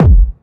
GS Phat Kicks 011.wav